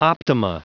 Prononciation du mot optima en anglais (fichier audio)
Prononciation du mot : optima